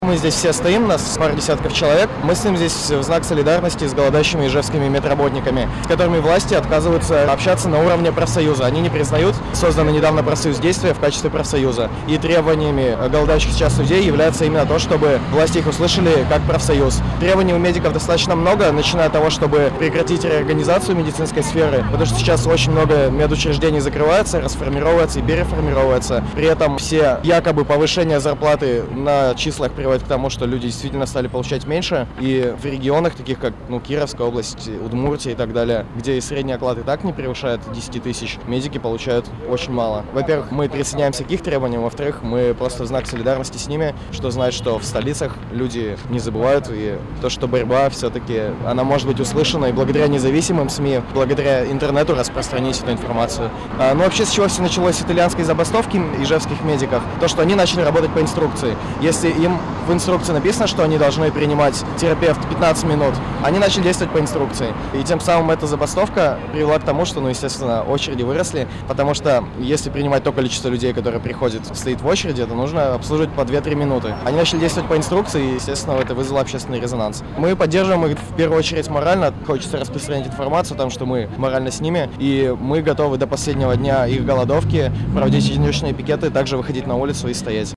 Пикетчик поддерживает врачей Ижевска